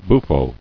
[buf·fo]